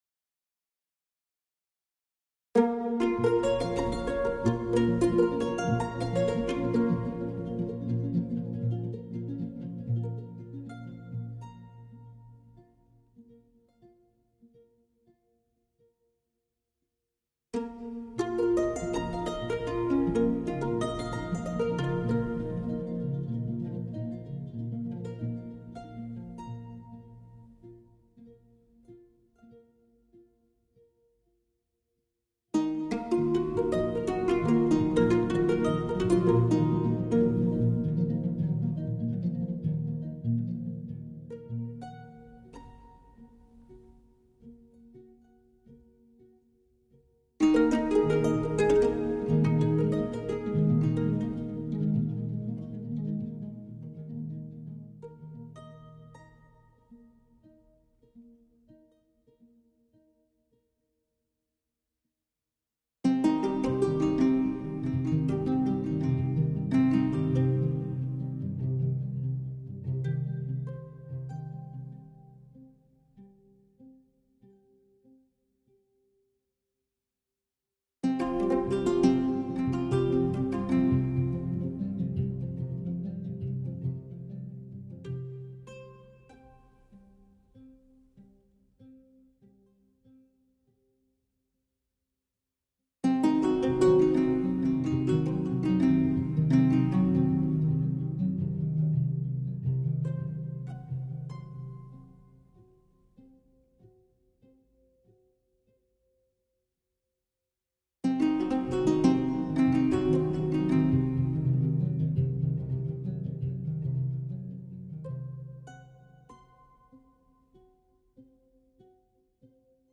reduced